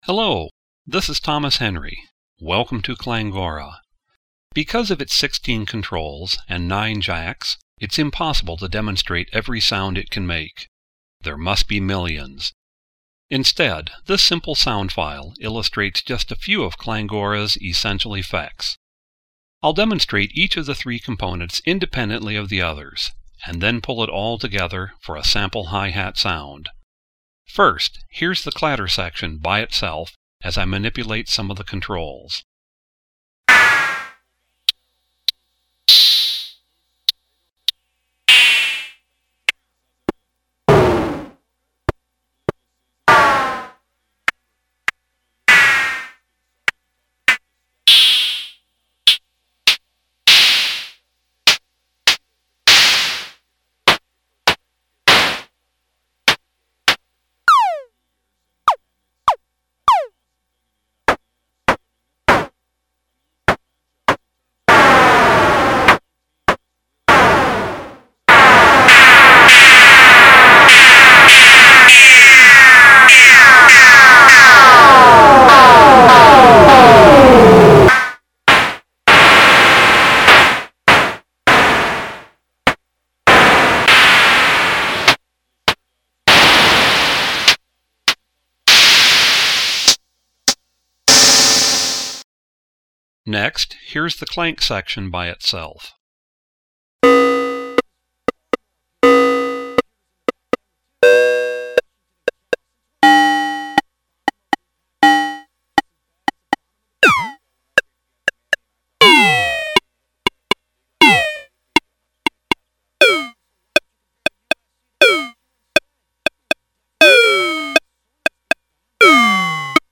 • Clangora Percussion Synth  - Apply VCO an designs to build an electronic hi-hat
clangora.mp3